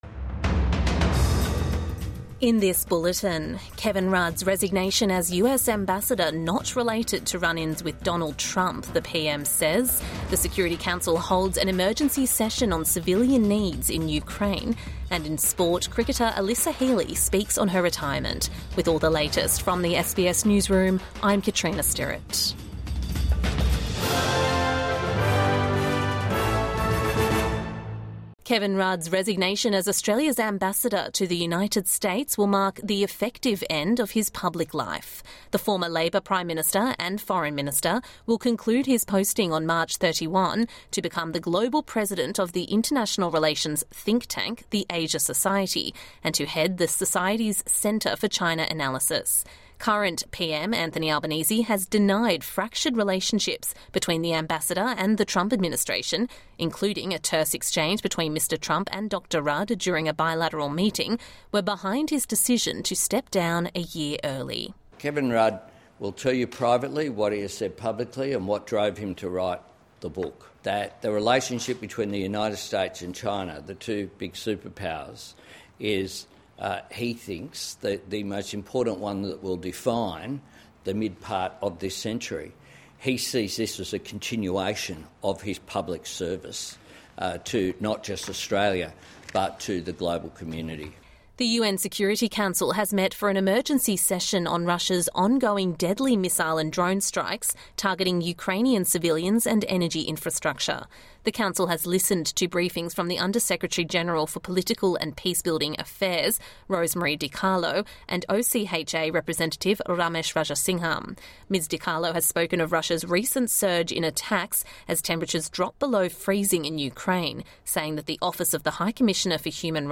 Security Council holds an emergency session on Ukraine | Midday News Bulletin 13 January 2026